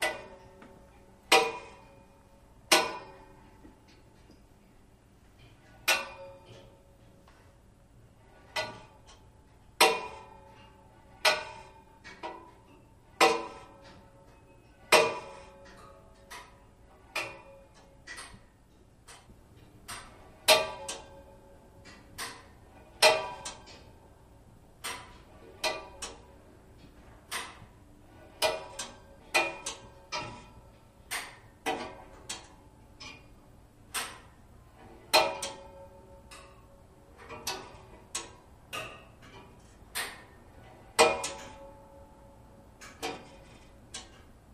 Heater Pipes Clanging Loop